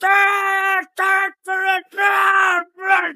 Fuse’s noise from “Computer”
oddbods-fuse-angry-3-hq.mp3